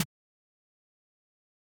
brush01.mp3